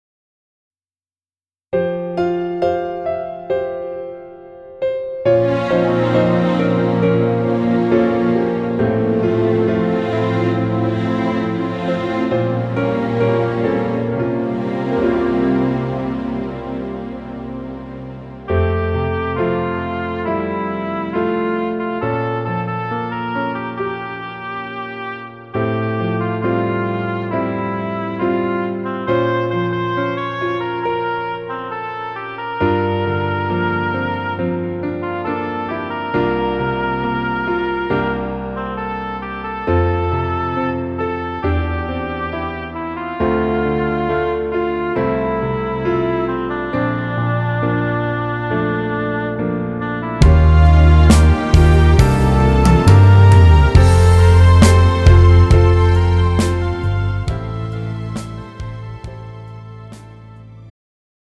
음정 멜로디MR
장르 축가 구분 Pro MR
가사   (1절 앞소절 -중간삭제- 2절 후렴연결 편집)